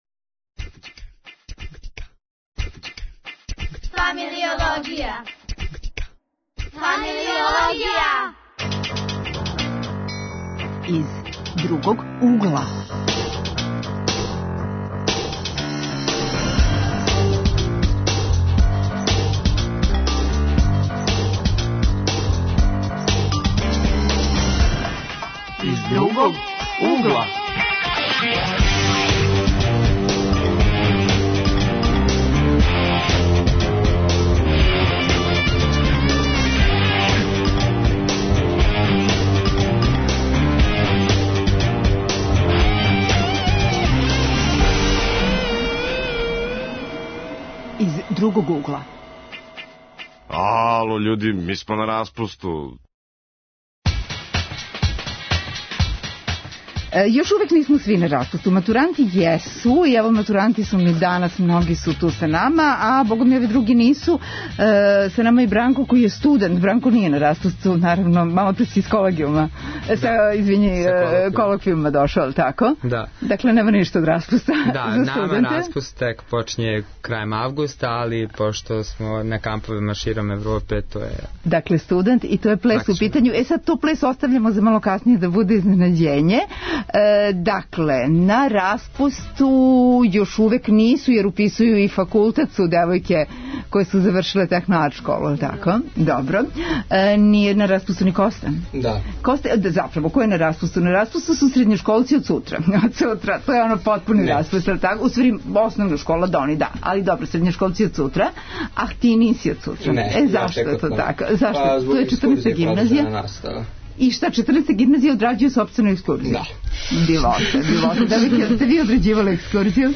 Гости - студенти савременог плеса, хип-хопери, улични плесачи, играчи у комичној опери "Служавка господарица" чија се премијера очекује следеће недеље у Народном позоришту у Београду.